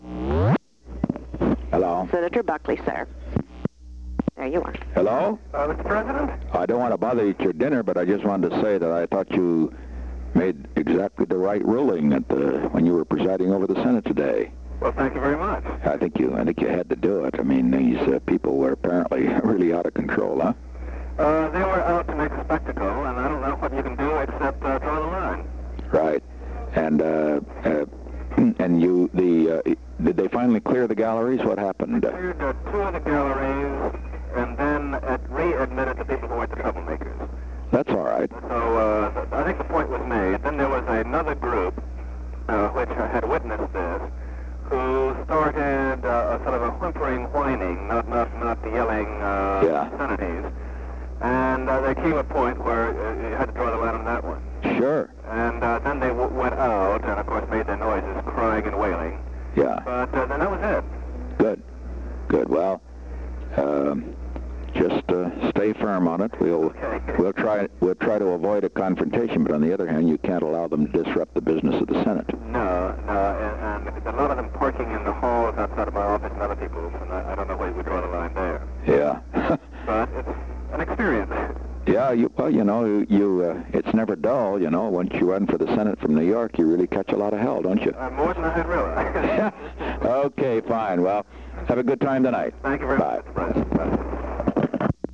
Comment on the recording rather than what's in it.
Secret White House Tapes Location: White House Telephone